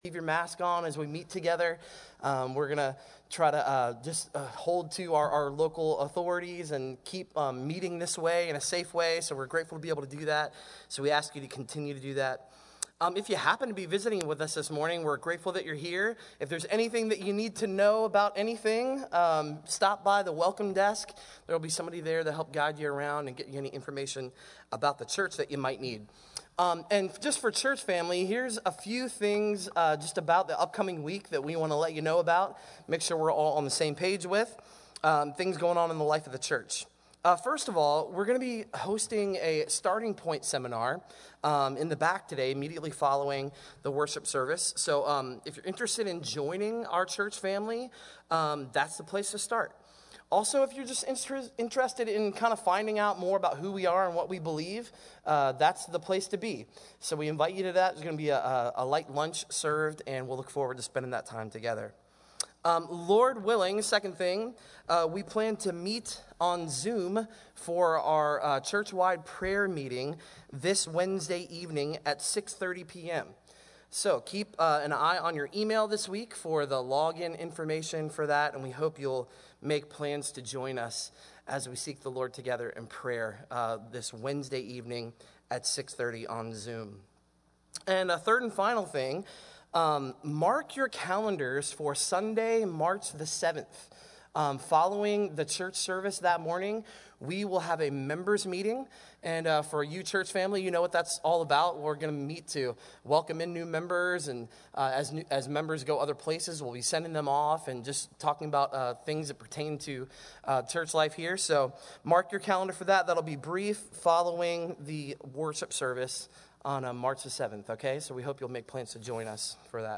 SERVICE-The-Lamb-of-God.mp3